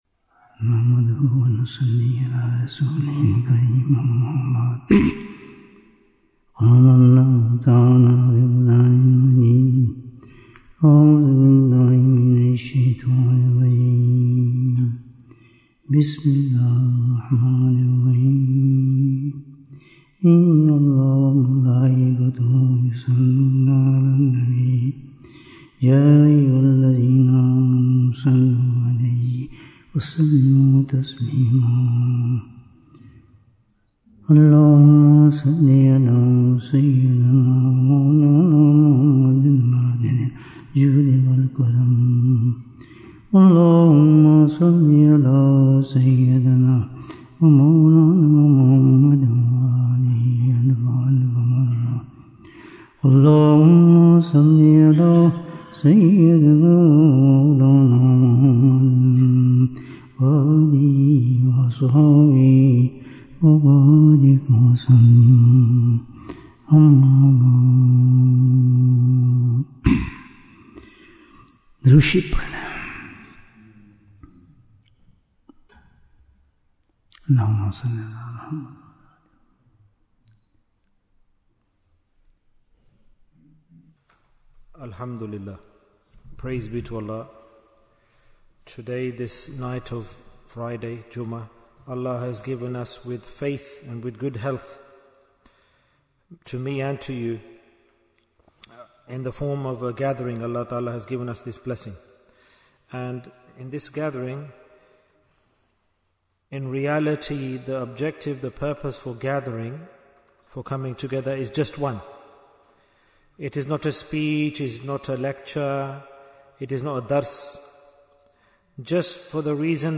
How Can We Become Pious? Bayan, 73 minutes16th October, 2025